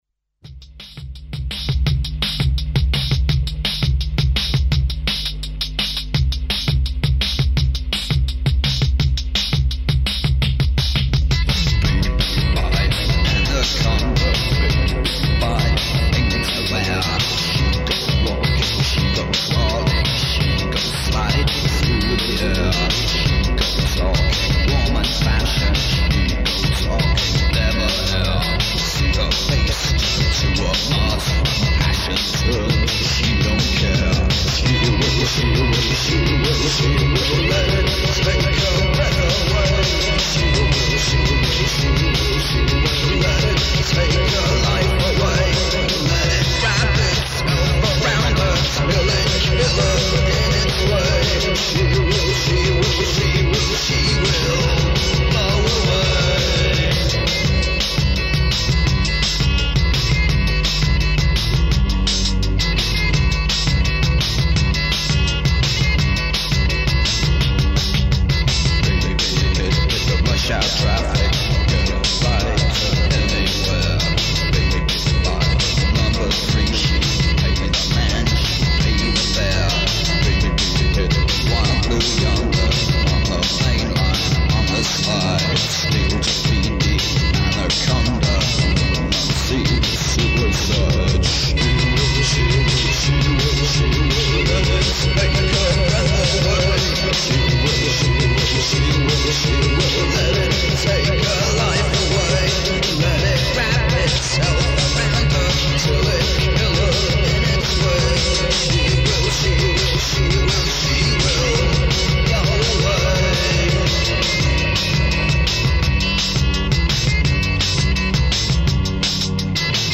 studio demo version